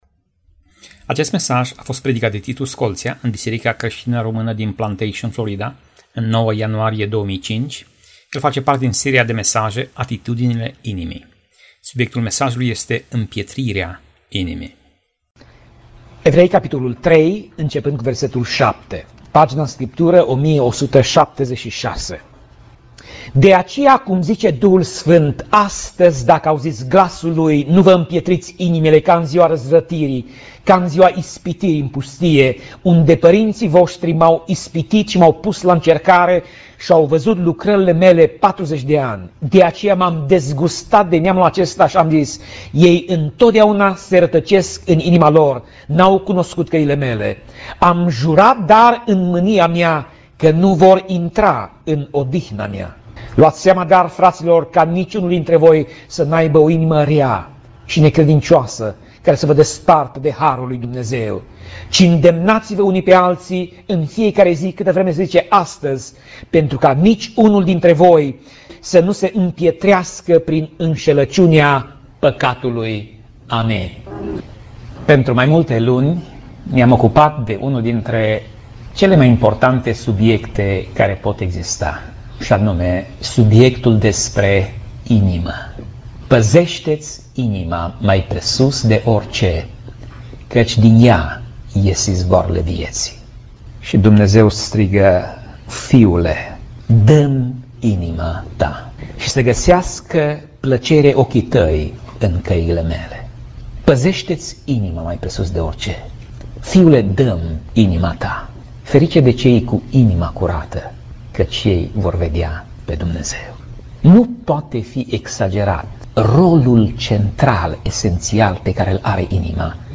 Pasaj Biblie: Evrei 3:7 - Evrei 4:1 Tip Mesaj: Predica